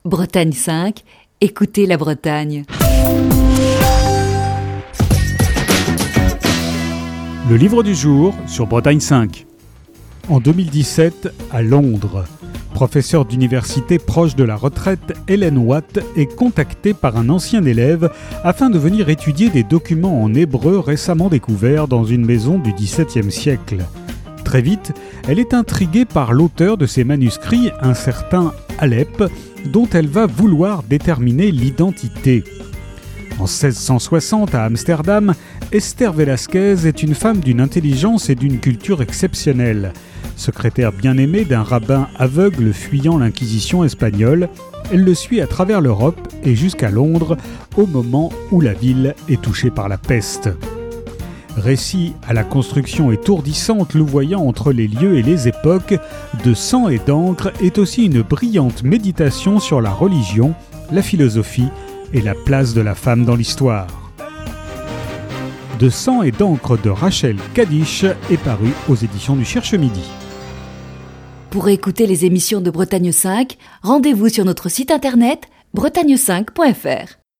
Chronique du 19 novembre 2020.